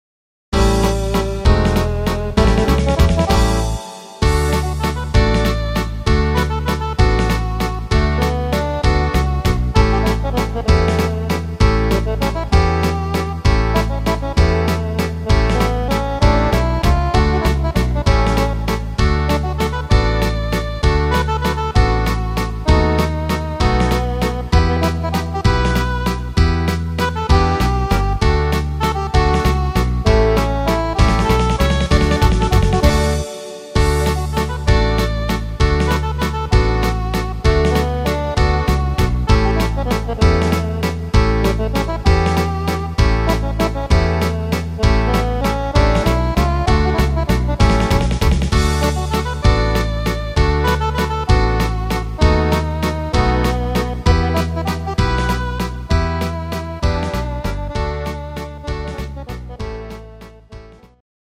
instr. Akkordeon